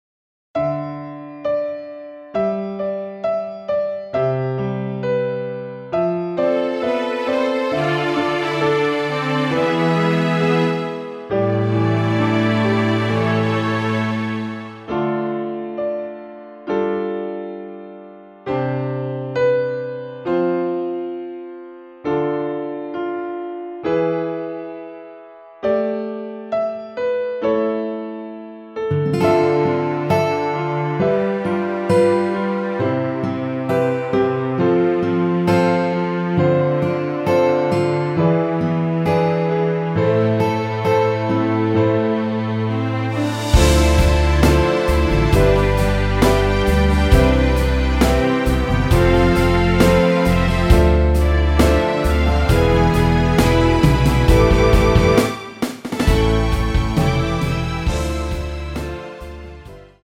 원키에서(-8)내린 MR입니다.
남성분이 부르실수 있는 키로 제작 하였습니다.
Am
앞부분30초, 뒷부분30초씩 편집해서 올려 드리고 있습니다.
중간에 음이 끈어지고 다시 나오는 이유는